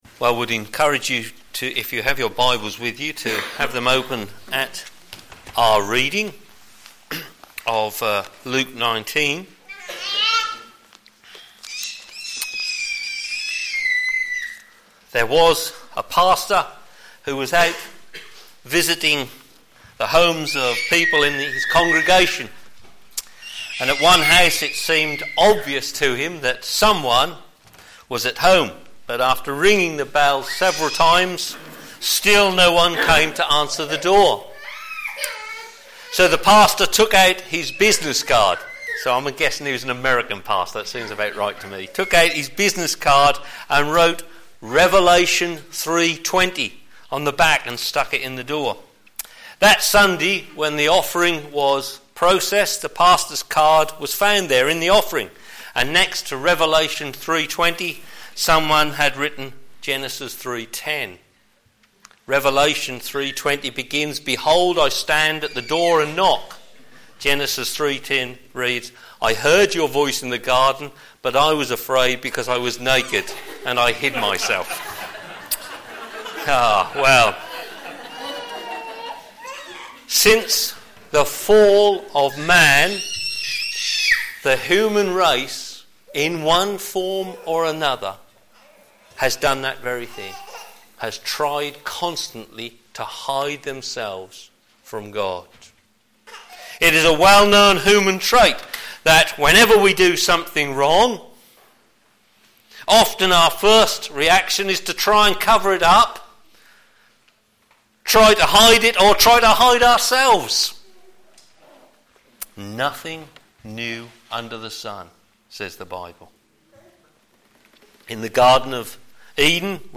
Media for a.m. Service on Sun 01st Jun 2014 10:30
Theme: Hiding from God Sermon